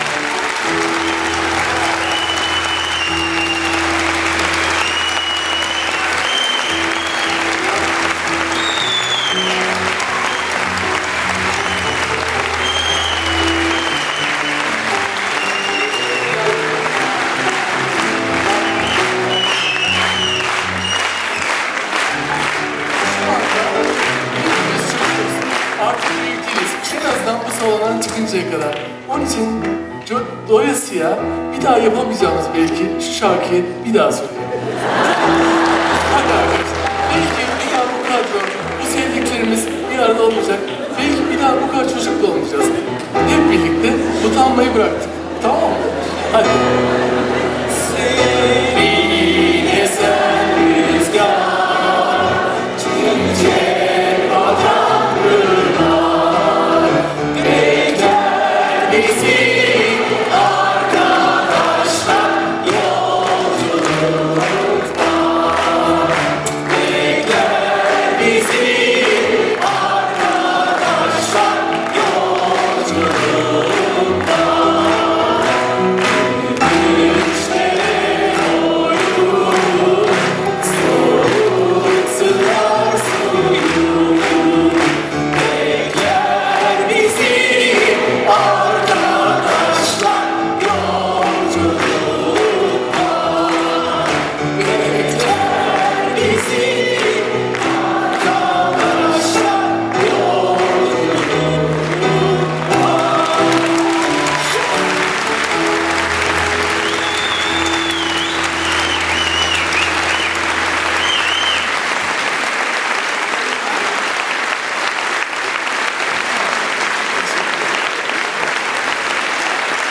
bir şarkıyı topluca kol kola, haykıra haykıra,
alkışlaya alkışlaya söyleyebilmek,